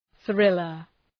Προφορά
{‘ɵrılər}